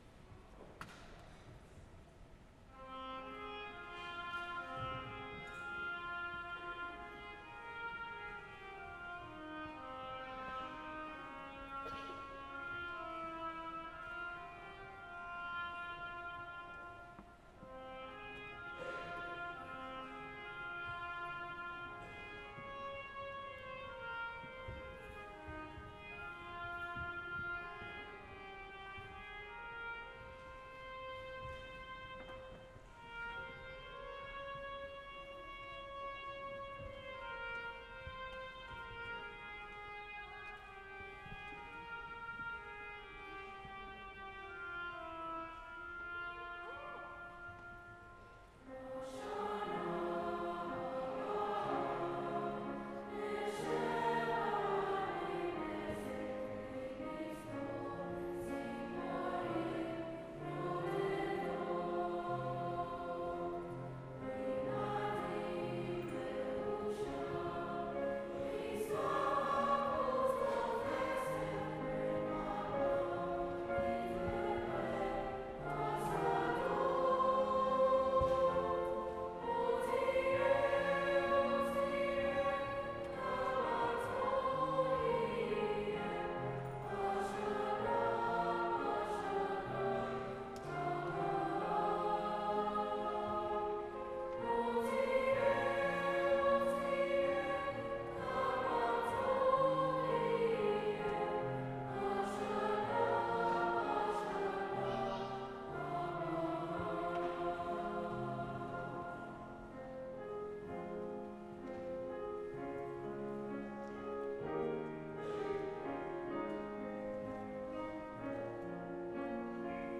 Bashana-Habaah-2018-Winter-Concert.mp3